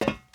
metalFootStep03.wav